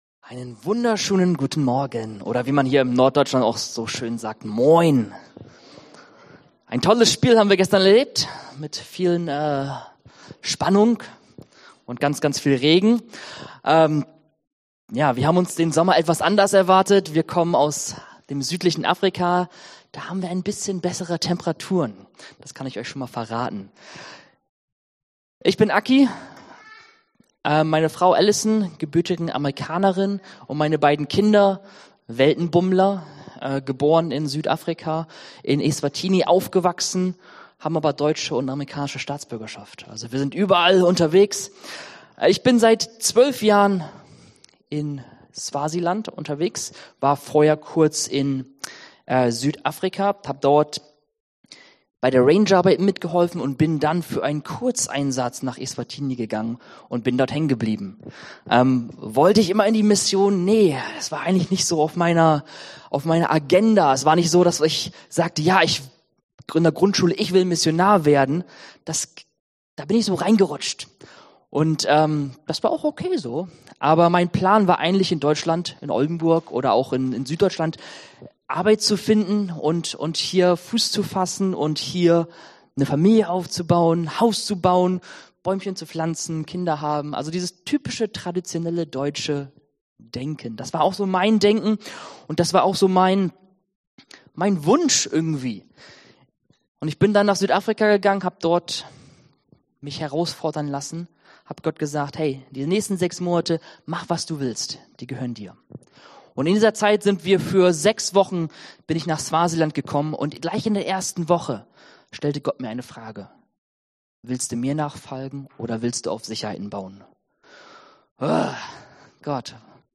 Dienstart: Predigt Themen: Jüngerschaft , Mission